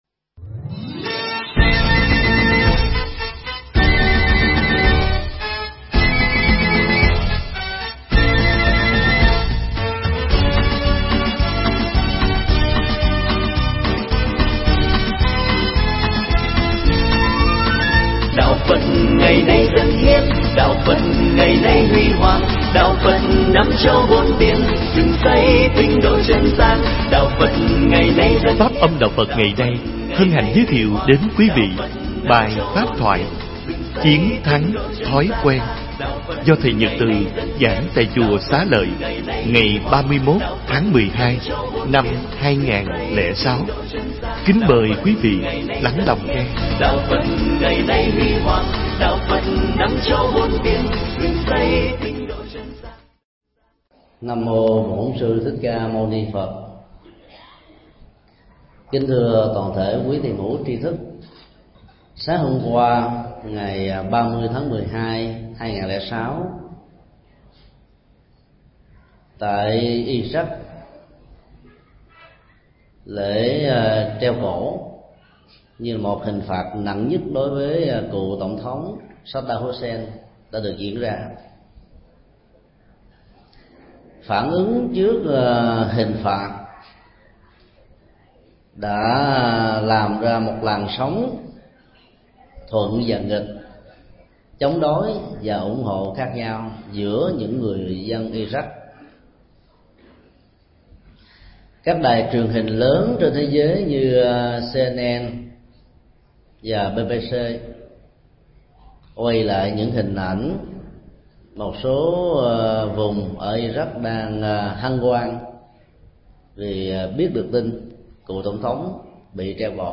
Bài giảng Chiến thắng thói quen
giảng tại chùa Xá Lợi